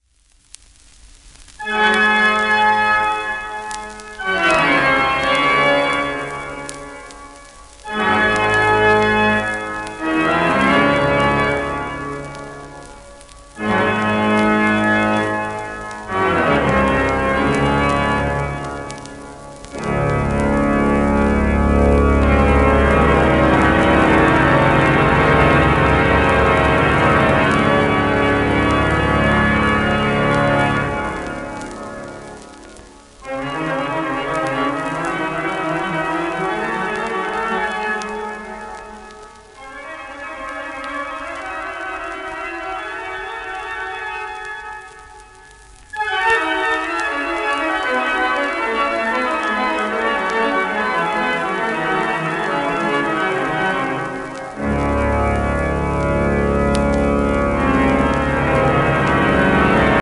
ハンブルグ、聖ミカエル教会オルガン
1928年頃録音